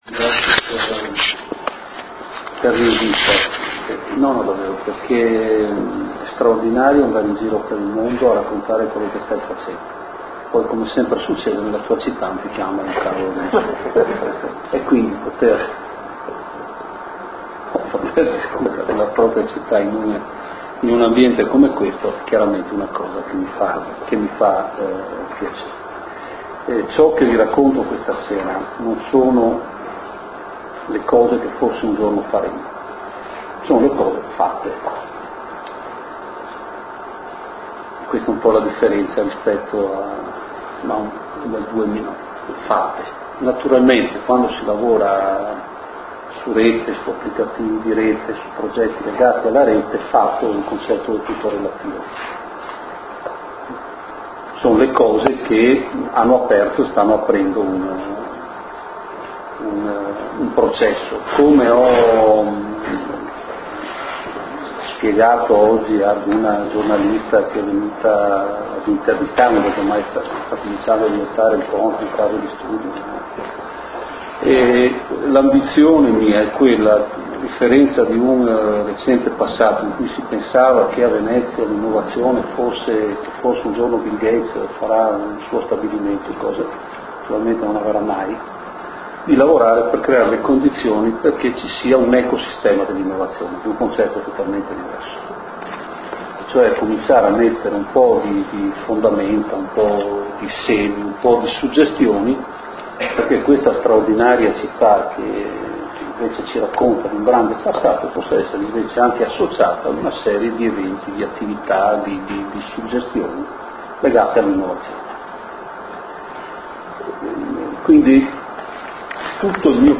aula A5, Cà Tron , Venezia